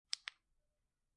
Backspace_Click.wav